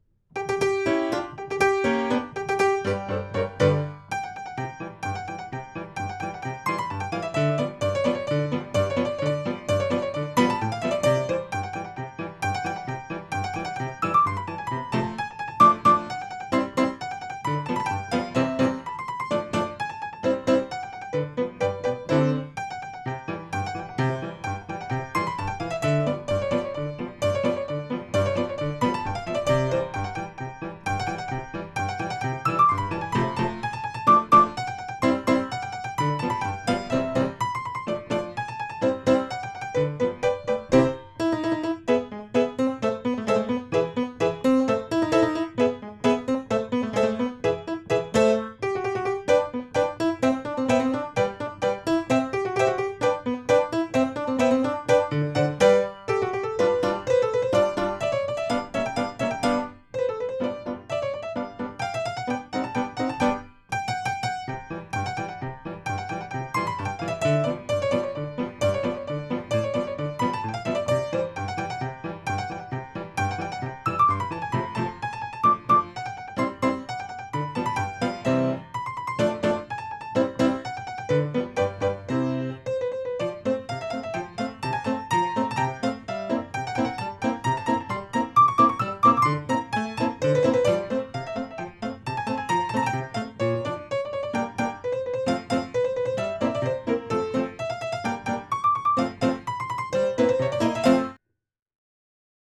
Notes: for piano
Galop